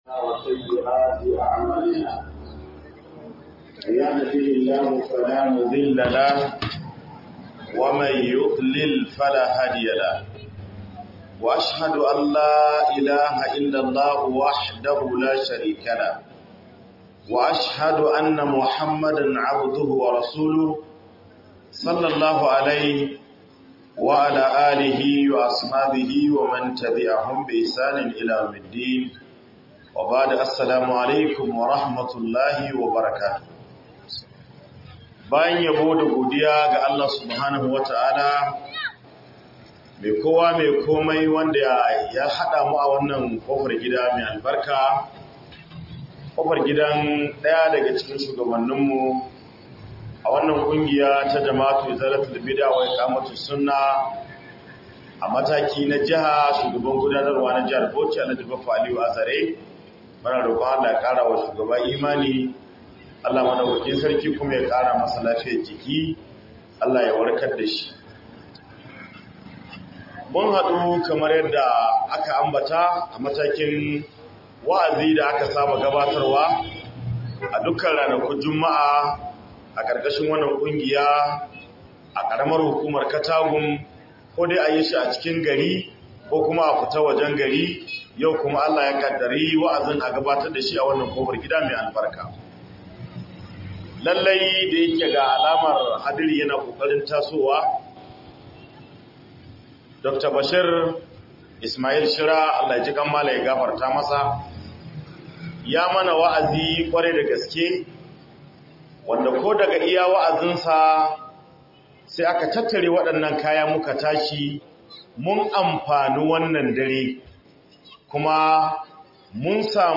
Bayan Wannan Rayuwar Akwai Wata Rayuwar - Muhadara